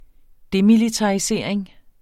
Udtale [ ˈdemilitɑiˌseɐ̯ˀeŋ ]